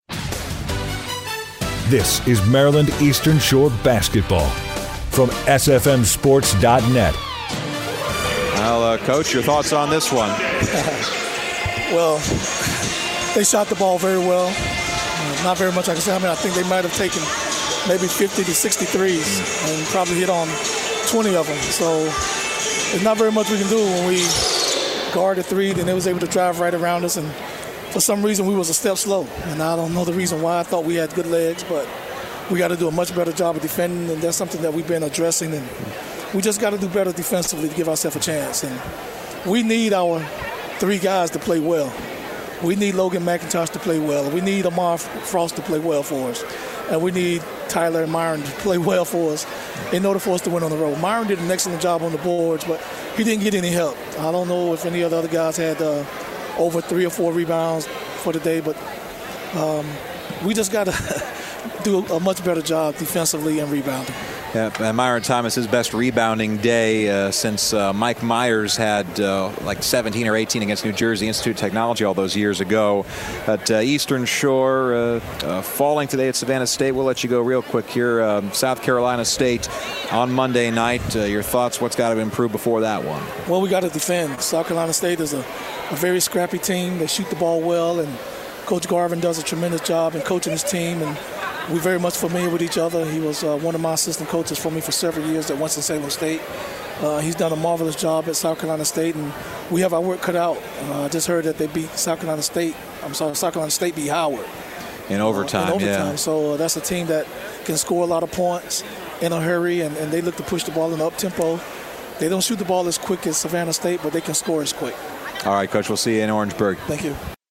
Post Game Interview